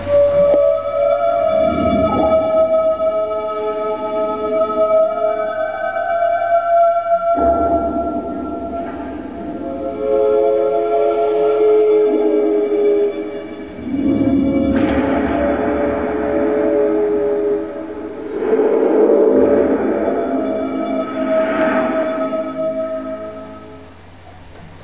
56k 100k 300k _____________ Thème musical